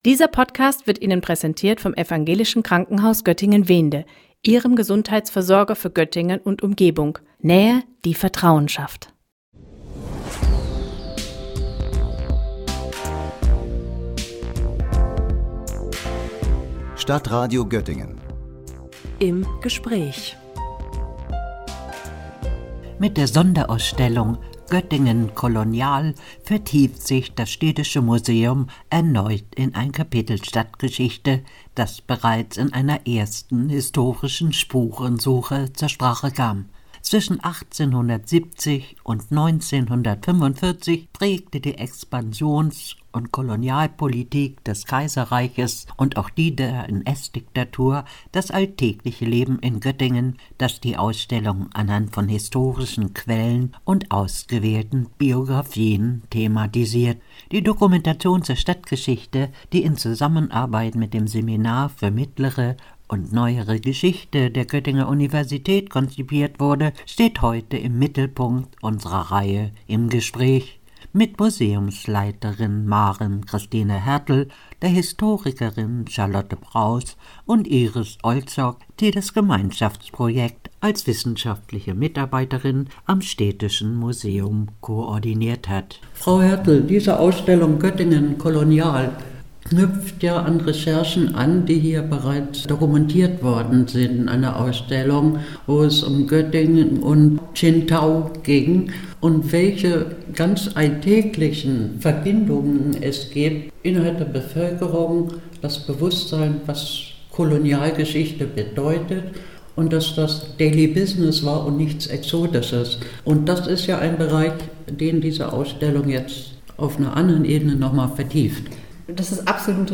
„Göttingen kolonial“ im Städtischen Museum – Gespräch